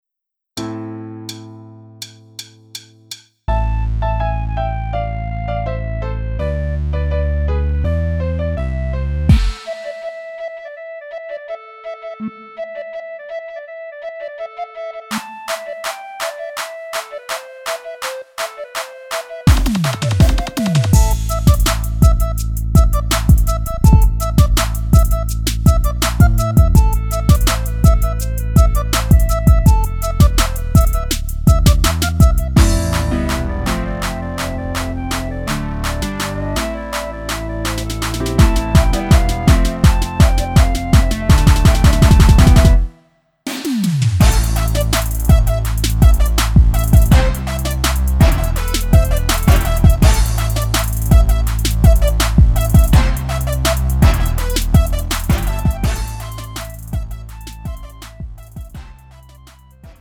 음정 원키 장르 가요